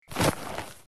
Звон сетки ворот от попавшего мяча